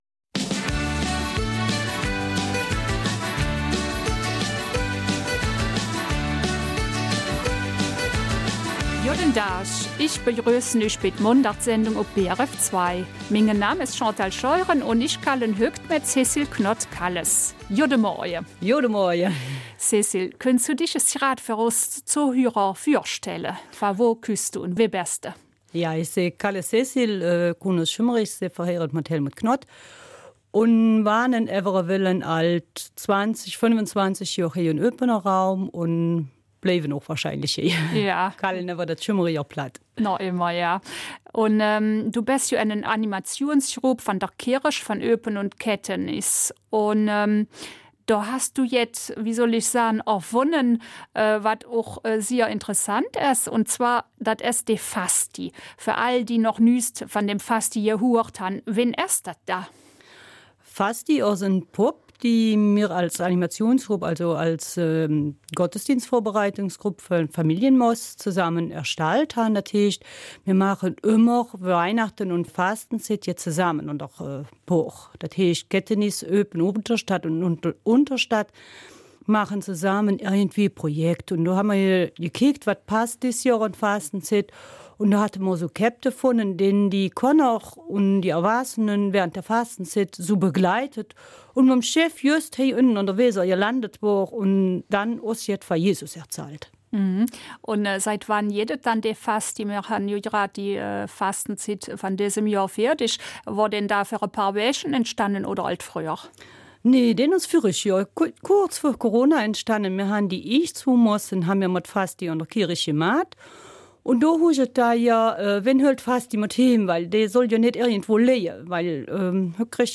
Eifeler Mundart: Fasti